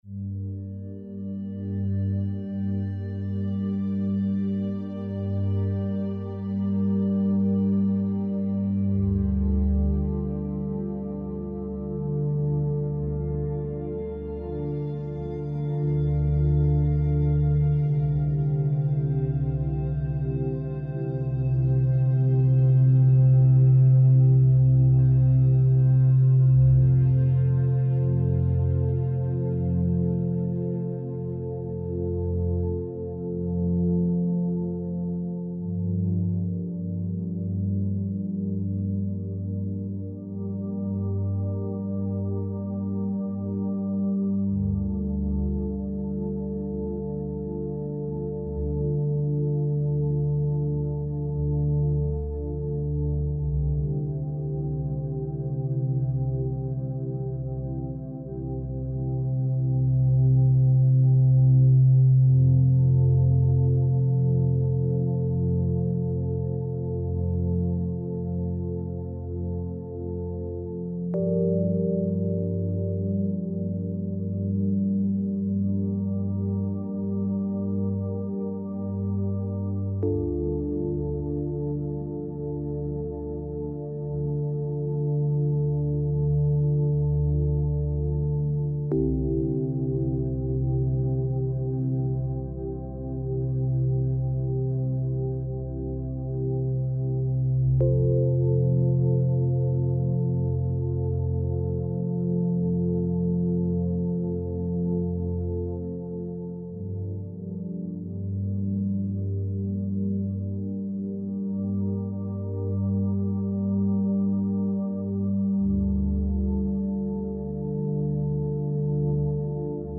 85hz - Gamma Binuaral Beats for Joy